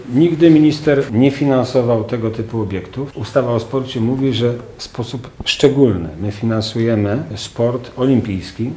– skomentował Ireneusz Raś.